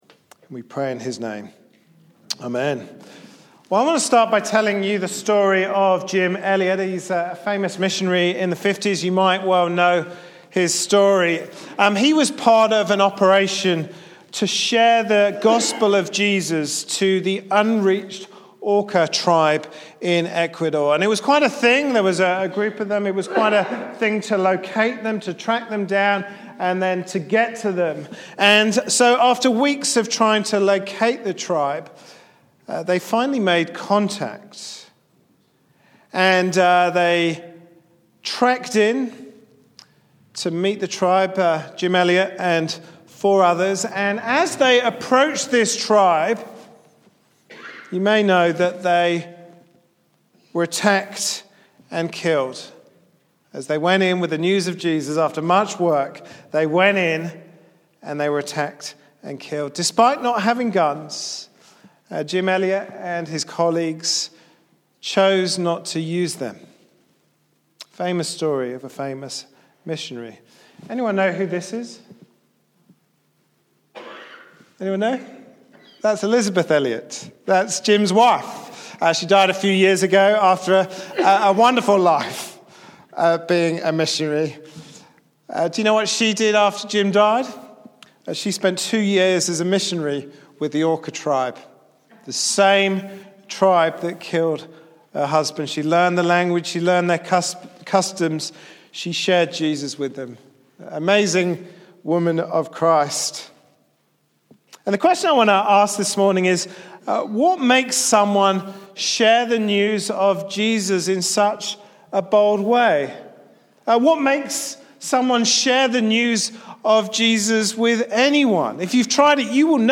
Passage: Acts 17:16-34 Service Type: Sunday evening service